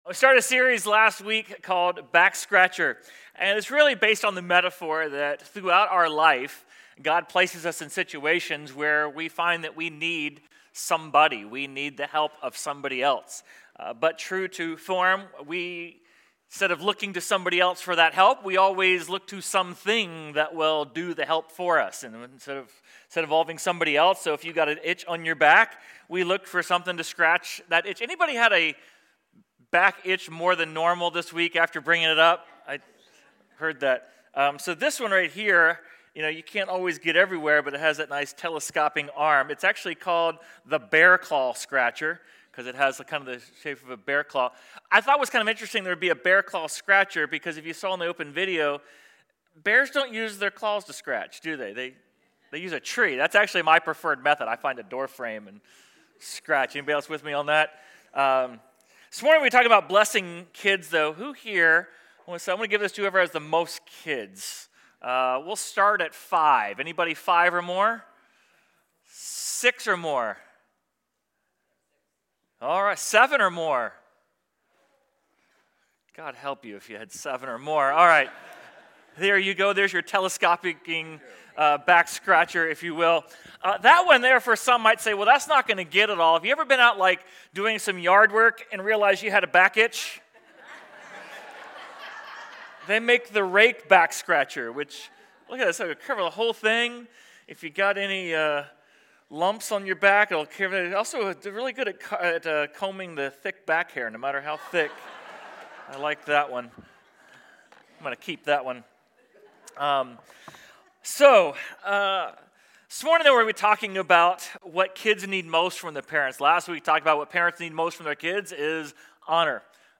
Sermon_4.19.26.mp3